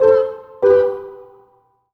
happy_collect_item_12.wav